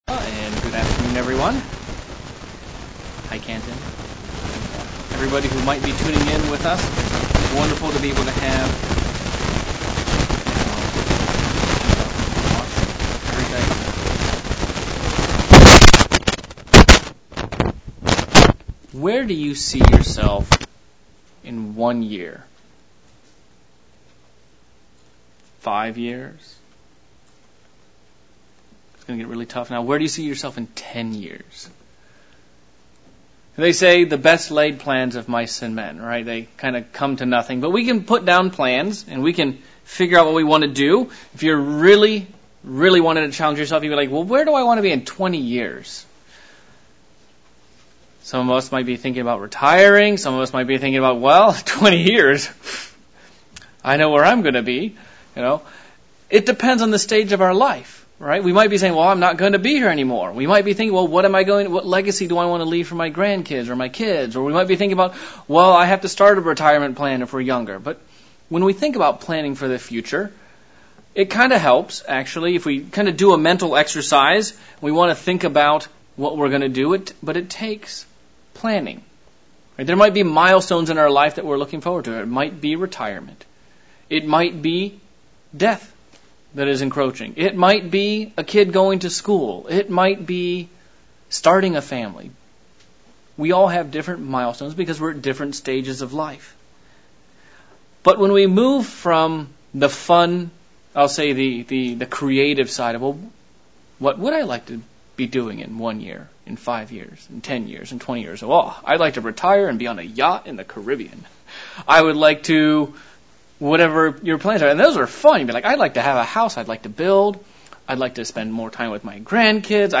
How do we go about building successful relationships? This sermon begins to cover several foundation elements of our most important relationship...the one with our Father in Heaven.